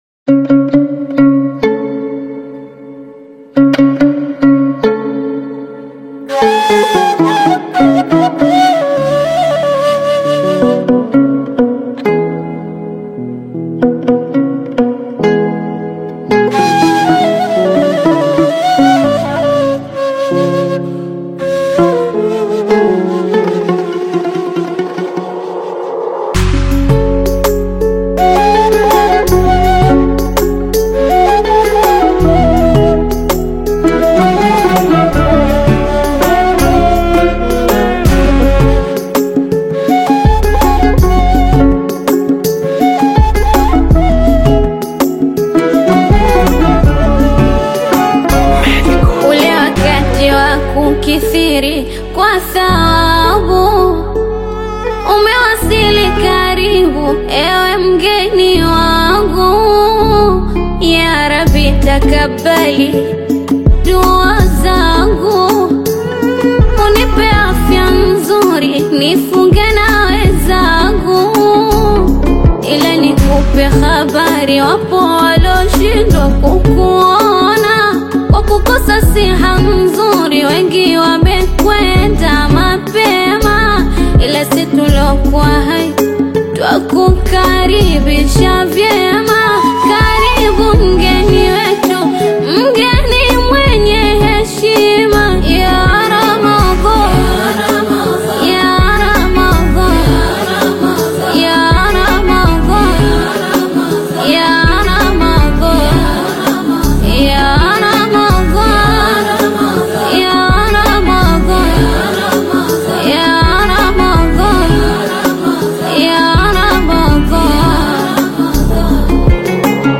heartfelt Gospel/Islamic devotional single